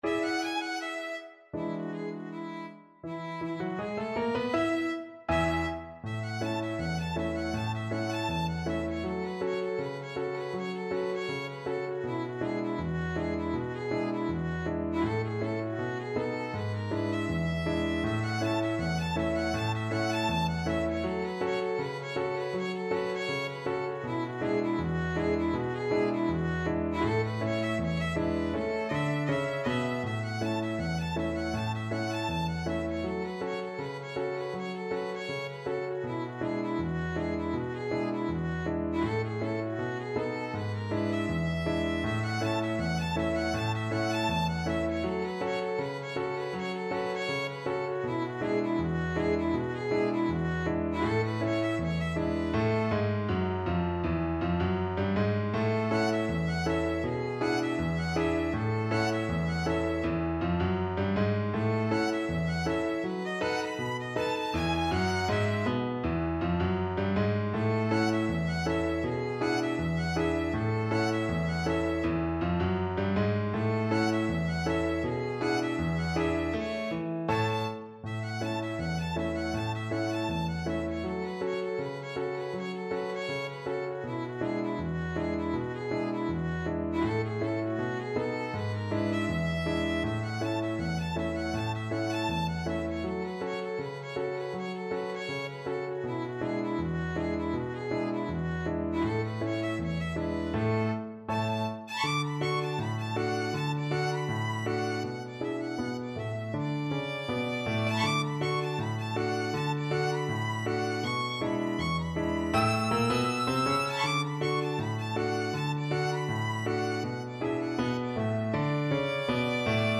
2/4 (View more 2/4 Music)
B4-E7
Jazz (View more Jazz Violin Music)
Rock and pop (View more Rock and pop Violin Music)